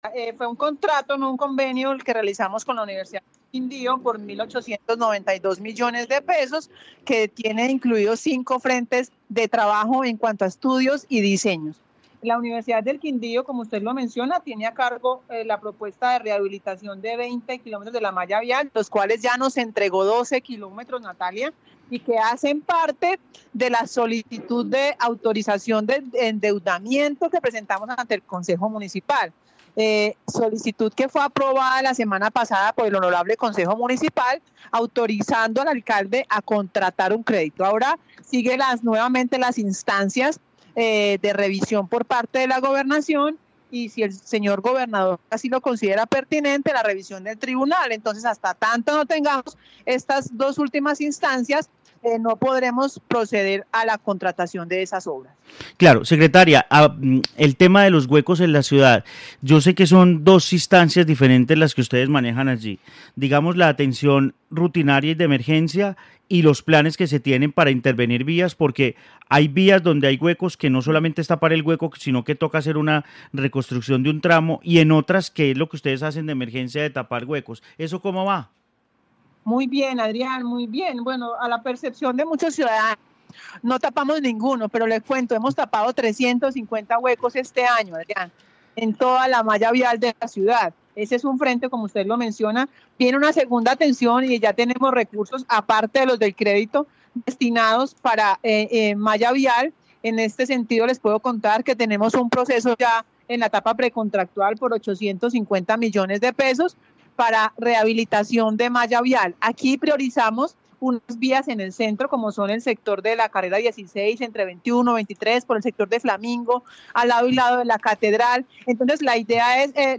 Claudia Arenas secretaría de infraestructura de Armenia